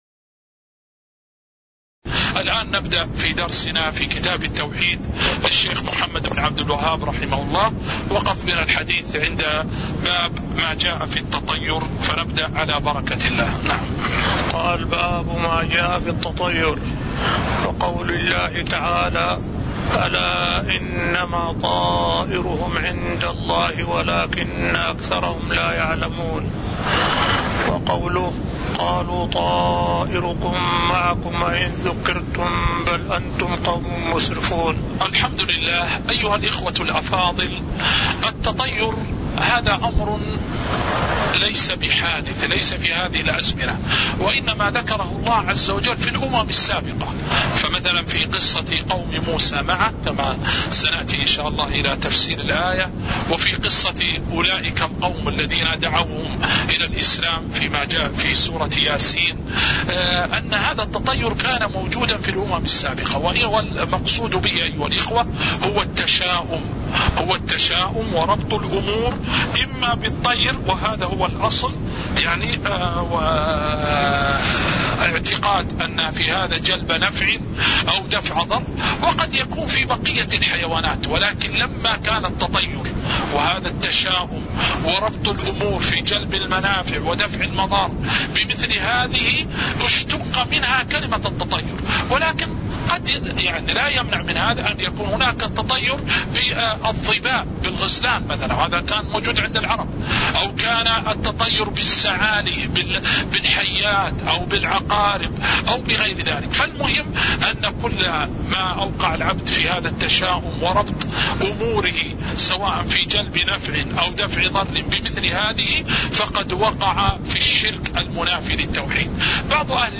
تاريخ النشر ١٧ شوال ١٤٤٠ هـ المكان: المسجد النبوي الشيخ